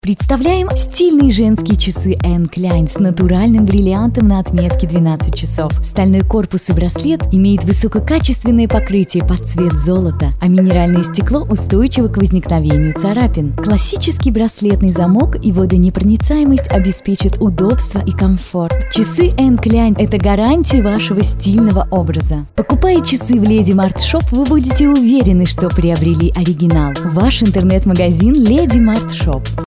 Микрофон студийный AKG C214 professional large diaphragm condencer , звуковой интерфейс UR44, поп фильтр, звуковой экран.